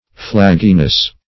Search Result for " flagginess" : The Collaborative International Dictionary of English v.0.48: Flagginess \Flag"gi*ness\, n. The condition of being flaggy; laxity; limberness.